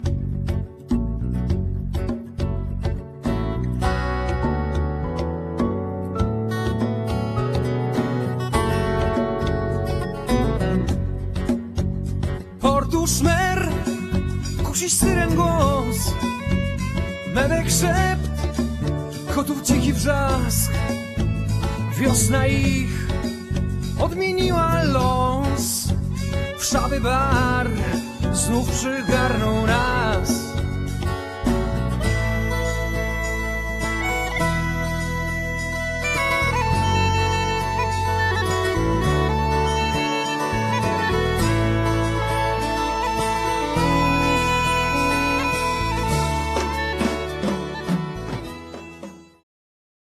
gitara basowa
skrzypce
perkusja, instr. perkusyjne
wokal, gitara